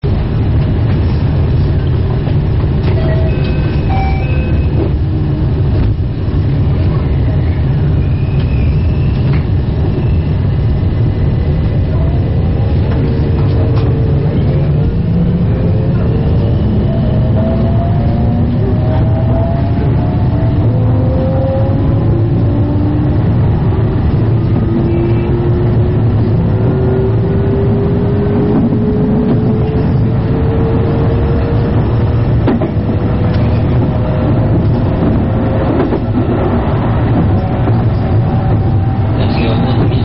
223系1000番台･2000番台 モーター音
ちょっと雑音が多いのもありますが、どうぞ聴いて下さい。
すべて、どこかの駅の出発の４０秒間を収録しています。